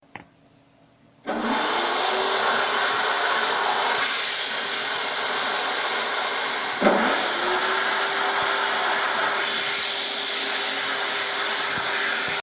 Aspiradora en funcionamiento
Grabación sonora en la que se escucha como alguien pone en funcionamiento una aspiradora para limpiar con ella.
electrodoméstico
Sonidos: Hogar